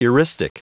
Prononciation du mot eristic en anglais (fichier audio)
eristic.wav